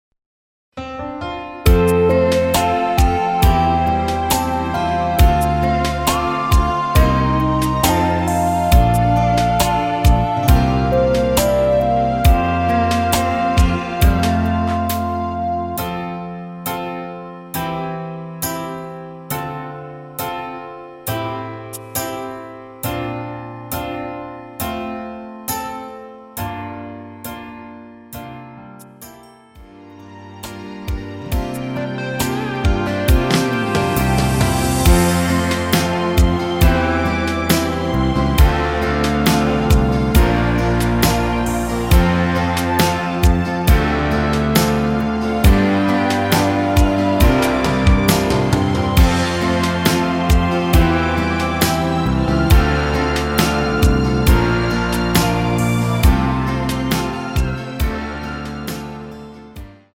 대부분의 여성분이 부르실수 있는 키로 제작 하였습니다.
원키에서(+4)올린 MR입니다.
앞부분30초, 뒷부분30초씩 편집해서 올려 드리고 있습니다.